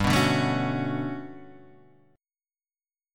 G Major Sharp 11th